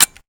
revolver_B_empty.wav